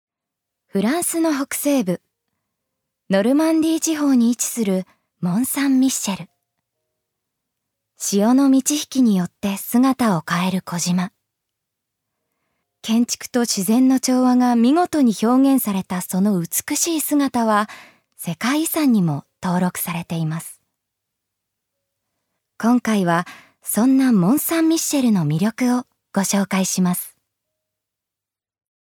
ジュニア：女性
ナレーション３